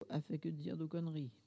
parole, oralité
Collectif atelier de patois
Catégorie Locution